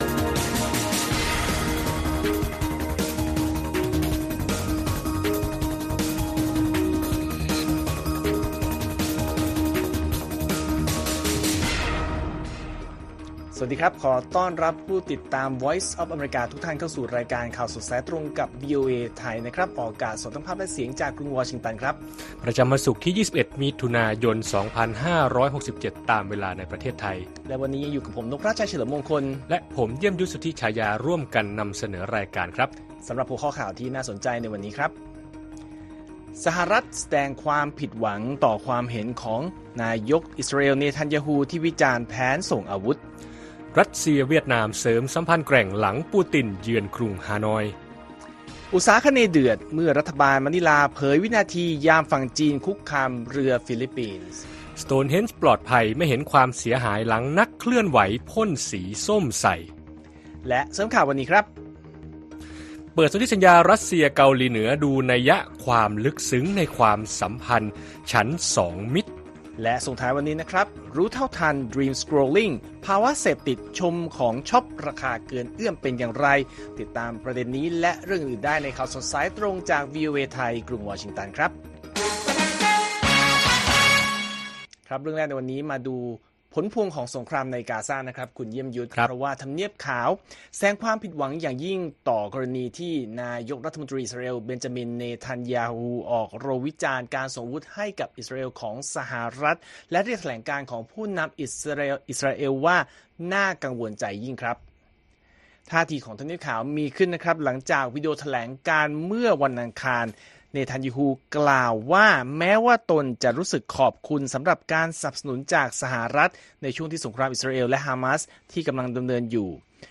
ข่าวสดสายตรงจากวีโอเอไทย 6:30 – 7:00 น. วันศุกร์ที่ 21 มิถุนายน 2567